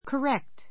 correct A1 kərékt コ レ クト 形容詞 正しい , 正確な the correct answer the correct answer 正しい答え Your answer is correct.